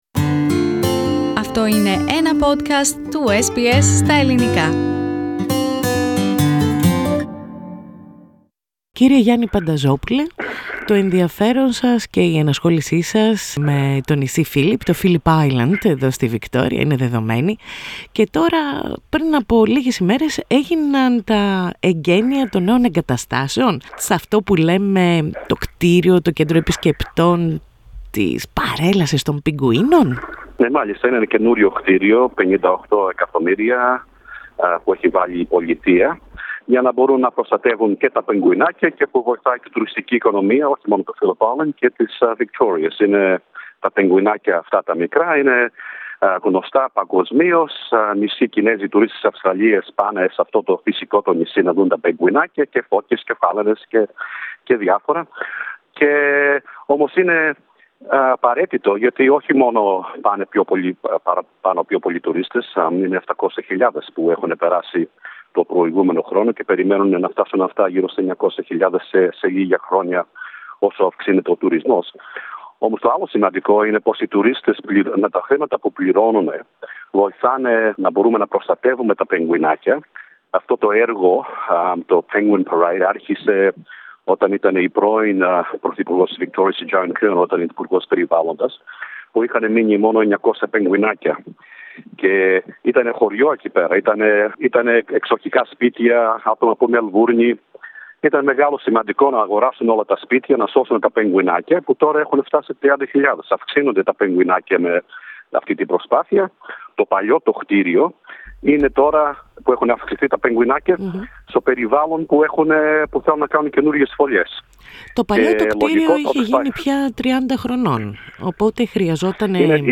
Για το έργο και την σημασία του στην προστασία των πιγκουίνων και του περιβάλλοντος, μίλησε στο Ελληνικό πρόγραμμα της ραδιοφωνίας SBS